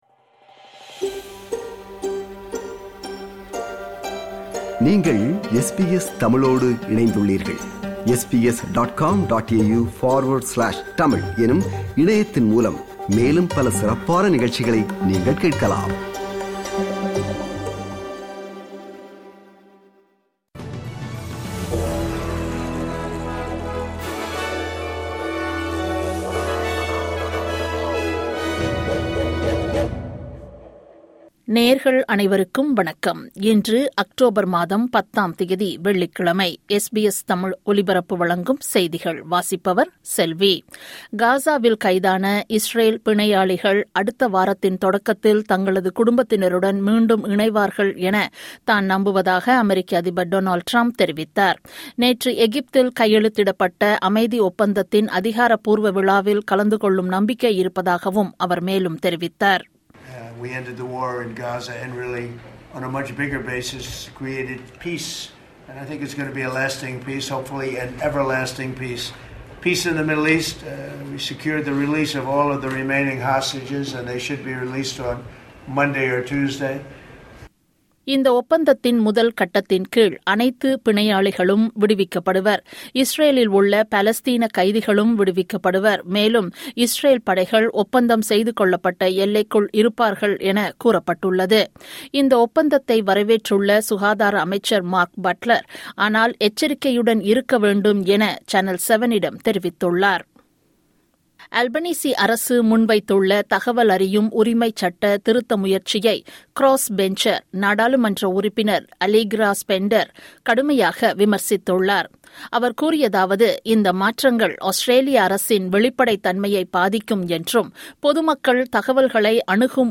இன்றைய செய்திகள்: 10 அக்டோபர் 2025 - வெள்ளிக்கிழமை
SBS தமிழ் ஒலிபரப்பின் இன்றைய (வெள்ளிக்கிழமை 10/10/2025) செய்திகள்.